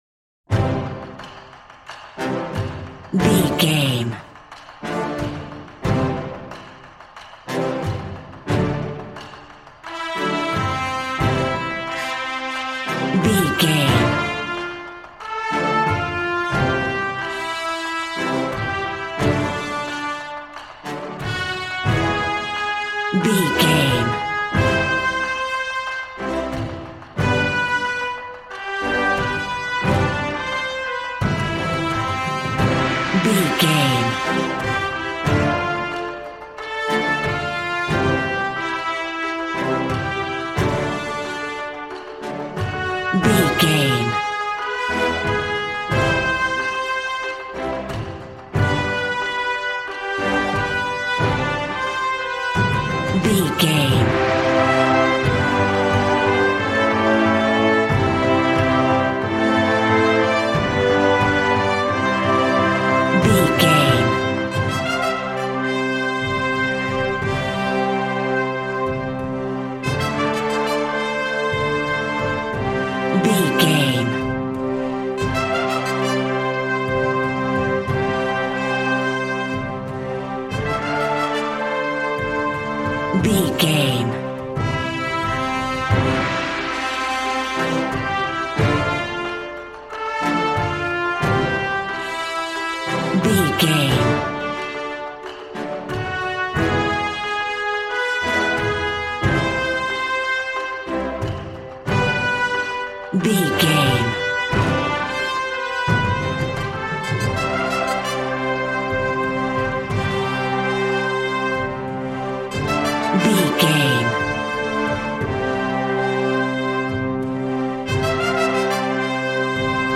Action and Fantasy music for an epic dramatic world!
Ionian/Major
hard
groovy
drums
bass guitar
electric guitar